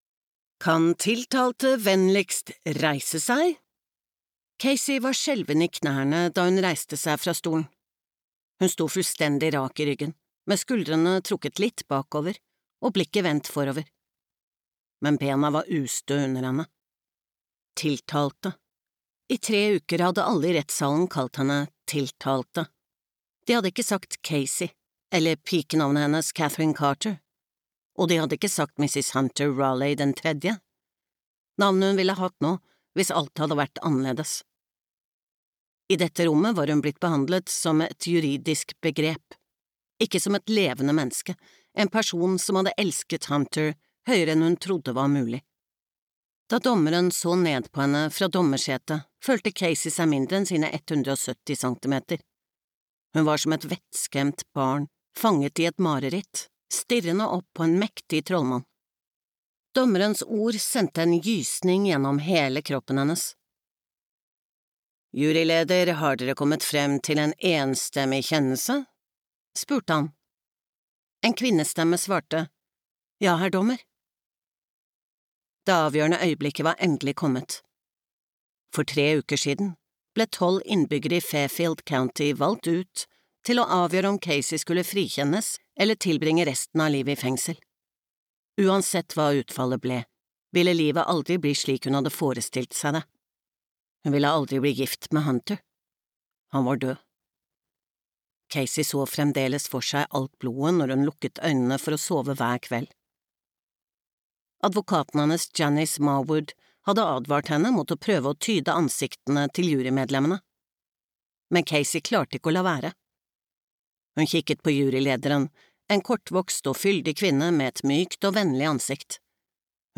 Tornerosemorderen (lydbok) av Alafair Burke